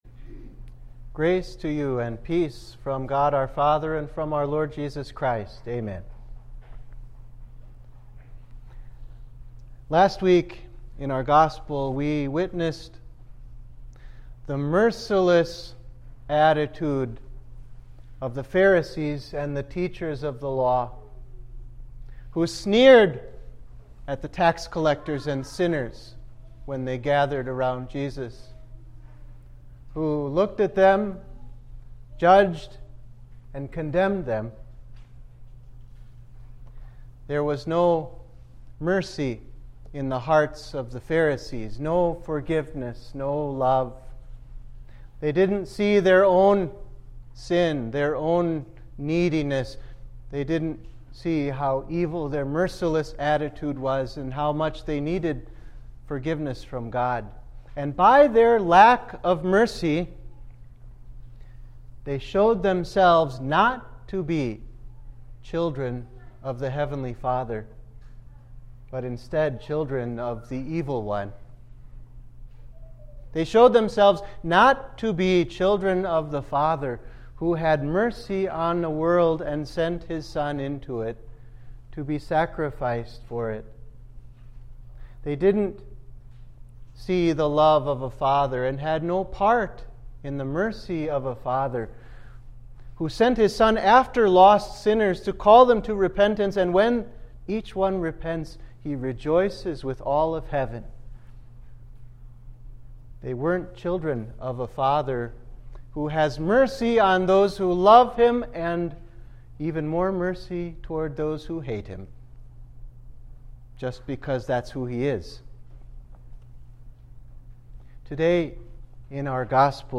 Sermon for the Fourth Sunday after Trinity